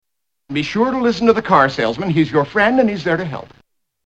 Tags: 3rd Rock from the Sun TV sitcom Dick Solomon John Lithgow Dick Solomon clips